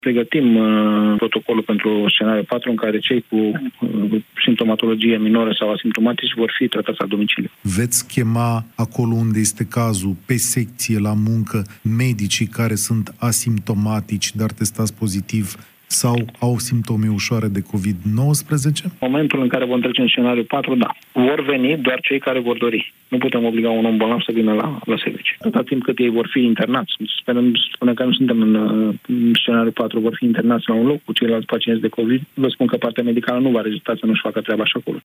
Astfel, toate cazurile ușoare de Covid 19 ar urma să fie tratate acasă, a declarat Ministrul Sănătății, Nelu Tătaru, într-o ediție specială a Știrilor Europa FM:
30mar-07-Nelu-Tataru-despre-scenariul-4-la-EFM.mp3